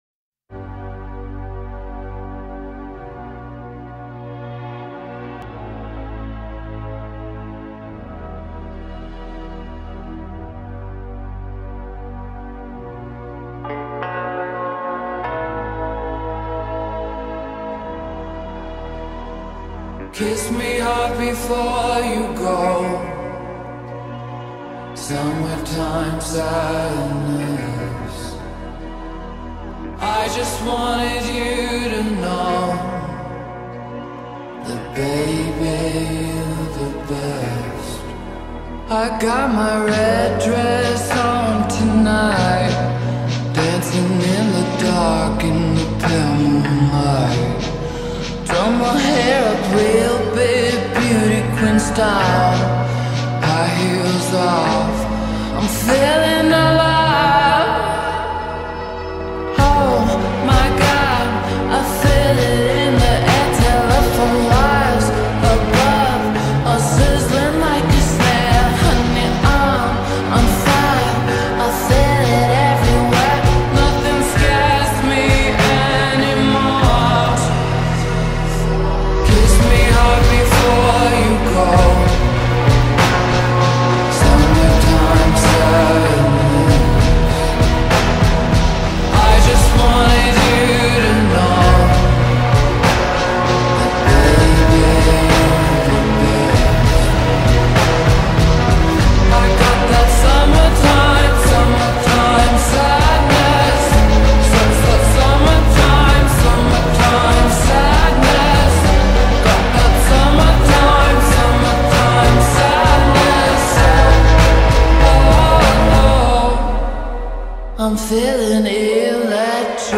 غمگین
عاشقانه